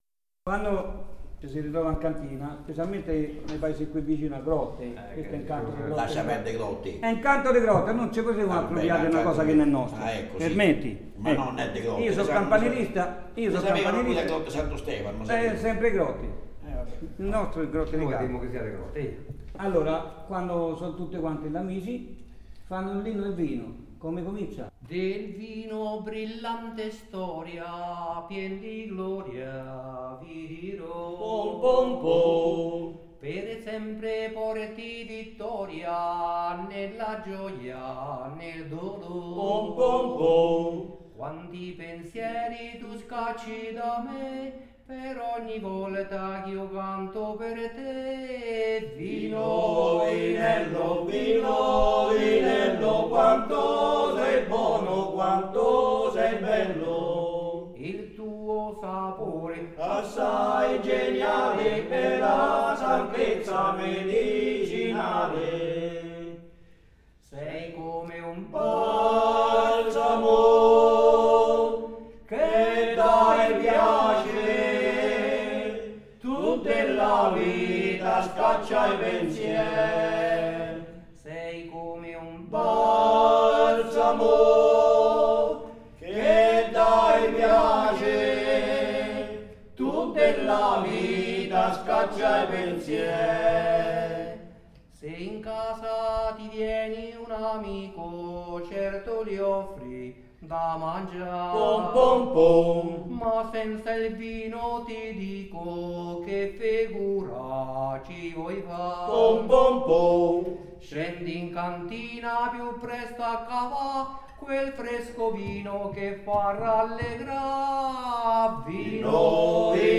Il paesaggio sonoro del territorio di Latera
Latera canta